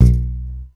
29. 29. Percussive FX 28 ZG